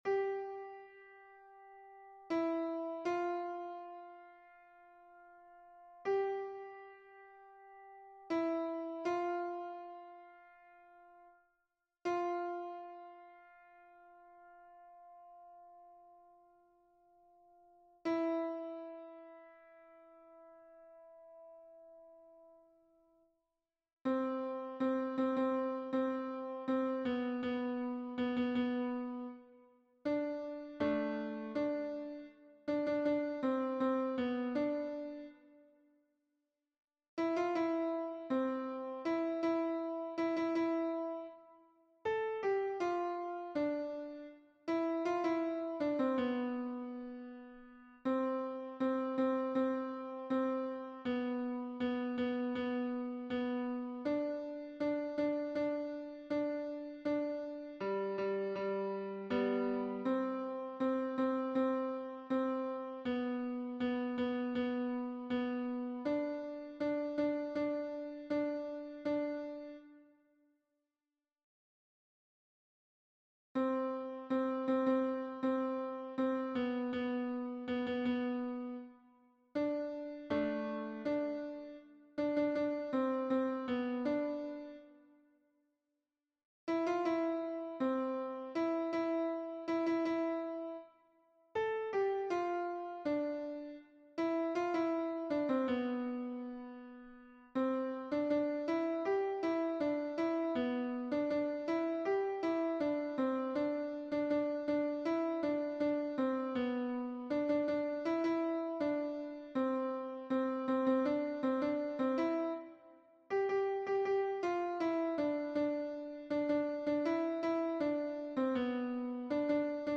MP3 version piano (les audios peuvent être téléchargés)
Voix 2 : alto et basse